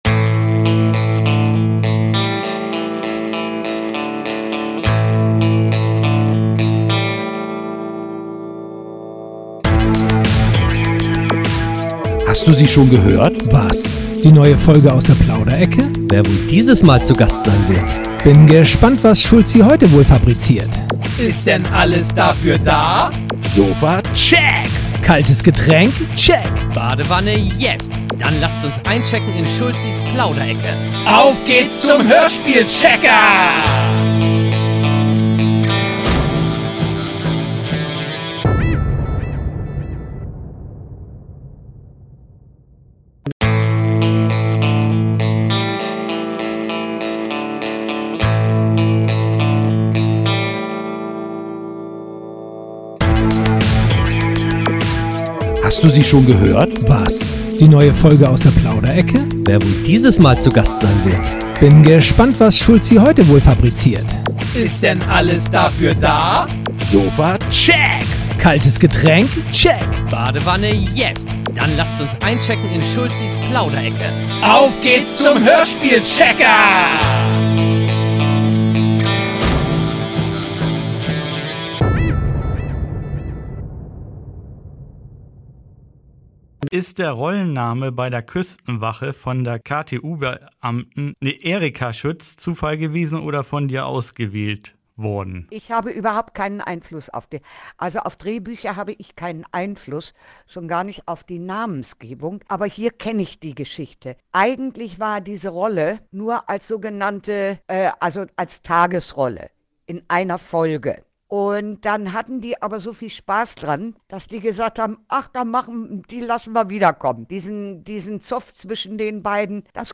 So alles wieder beim alten liebe Hörer diese Fortsetzung startet wie immer mit der Jingle zu Beginn.